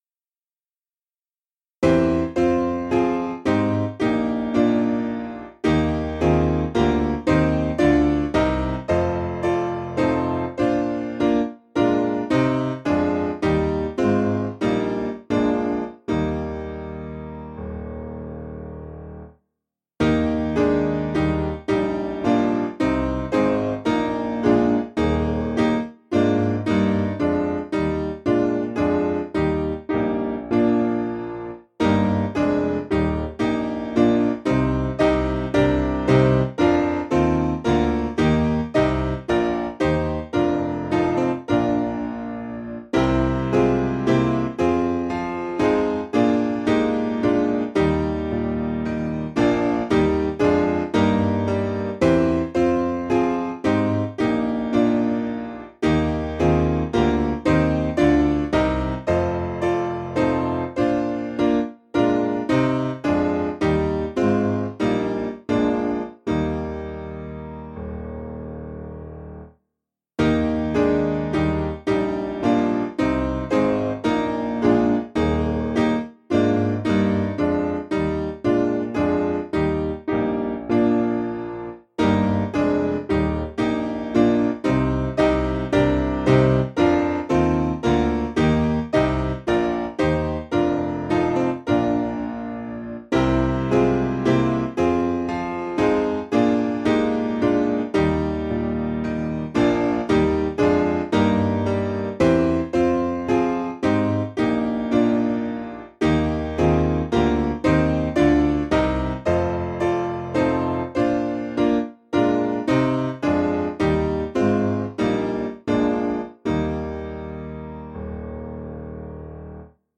Simple Piano
(CM)   5/Eb 493.6kb